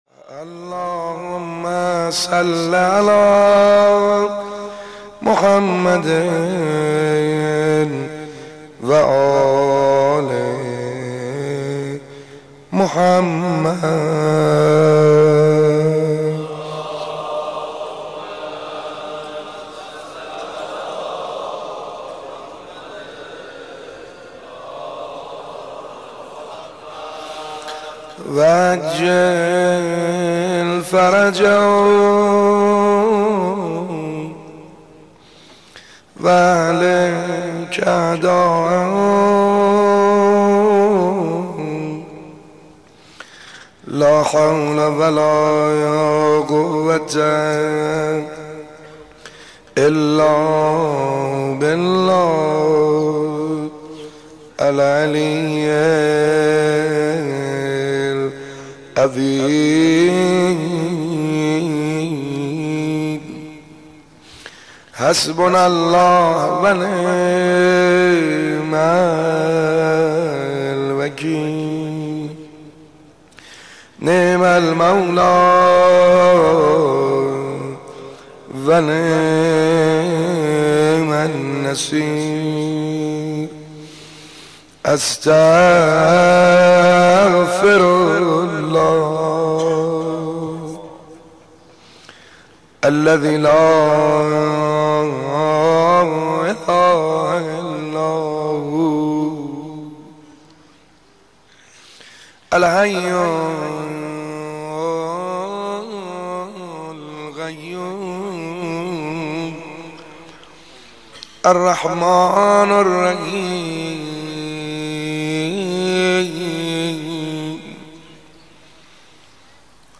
سینه زنی جهاد